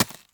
default_ice_dig.1.ogg